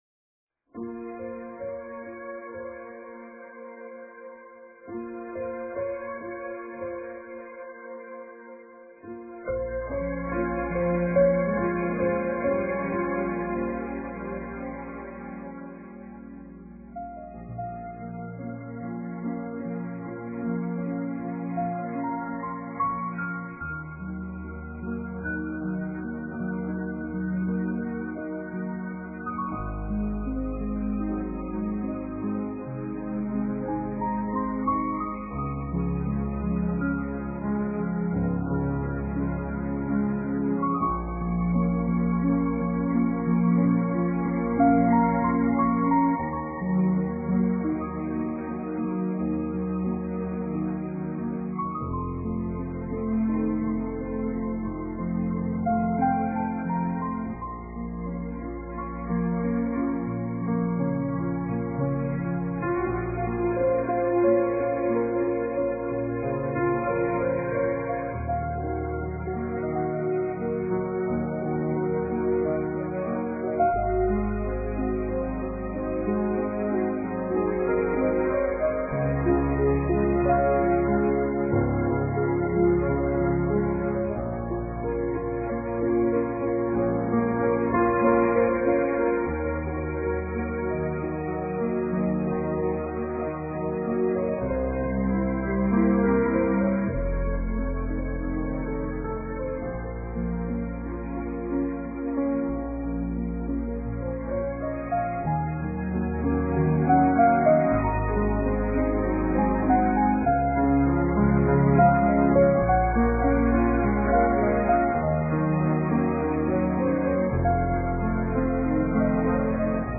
ORQUESTAS